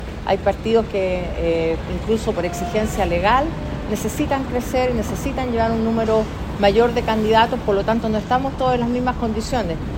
Por su parte, en el Socialismo Democrático, la presidenta del Partido Socialista, Paulina Vodanovic, le bajó el perfil a la salida de Acción Humanista y los Regionalistas Verdes de la lista.